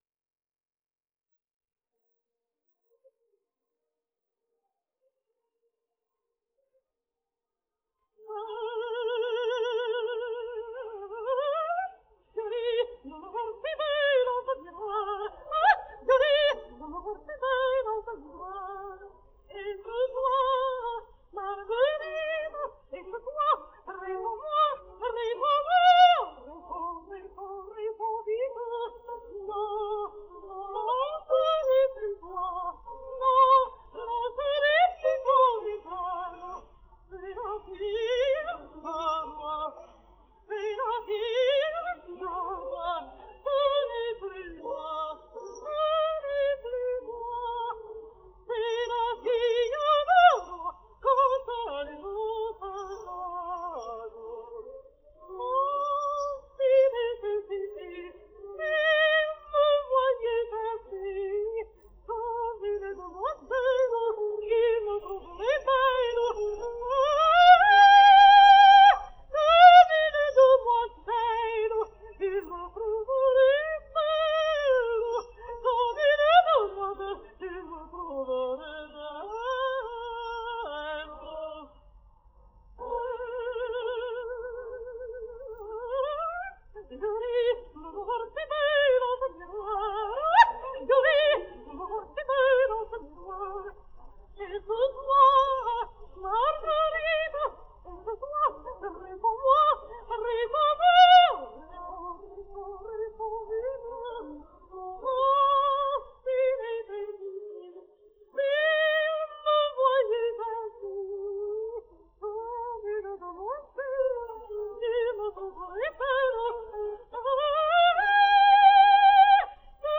denoised_vocals.wav